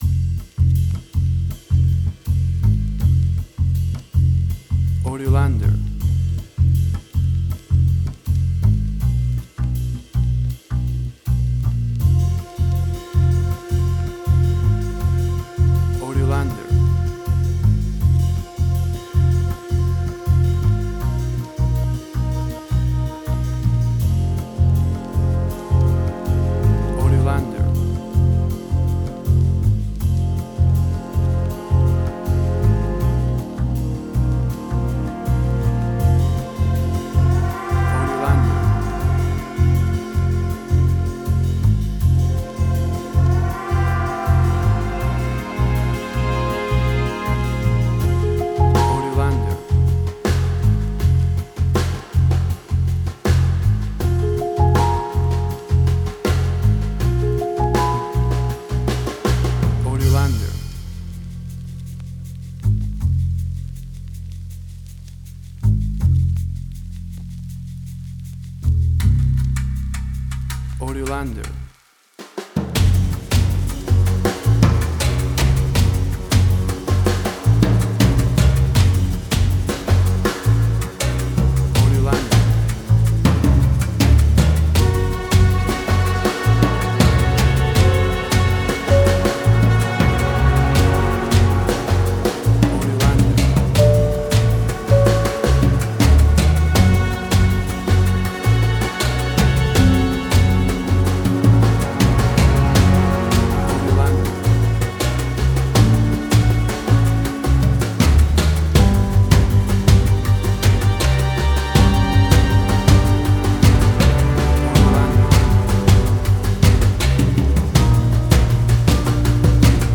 Modern Film Noir.
Tempo (BPM): 80